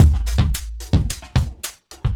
Index of /90_sSampleCDs/USB Soundscan vol.46 - 70_s Breakbeats [AKAI] 1CD/Partition B/26-110LOOP A